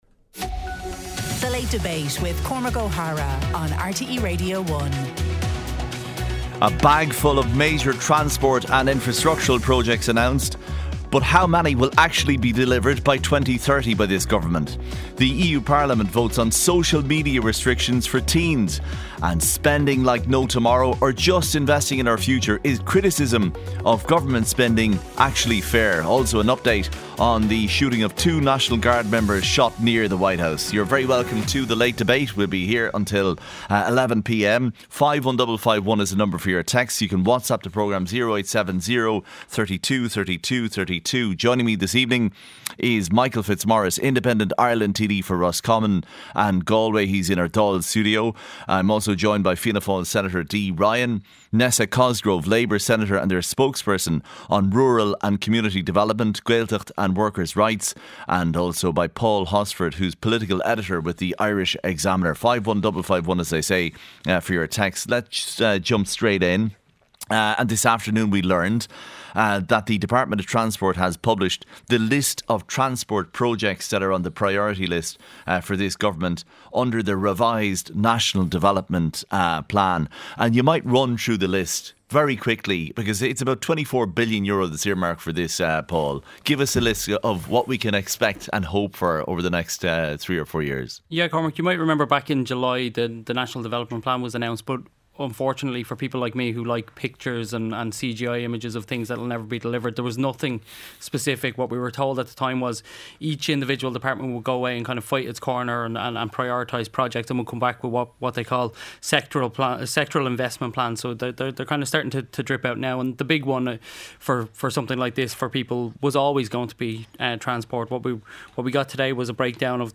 The Department of Transport published the list of transport projects that are on the priority list for this government under the Revised National Development Plan, this list is discussed by Fianna Fail Senator Dee Ryan, Labour Senator Nessa Cosgrove, Independent Ireland TD Michael Fitzmaurice and